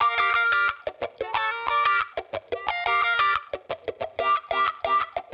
Index of /musicradar/sampled-funk-soul-samples/90bpm/Guitar
SSF_StratGuitarProc2_90G.wav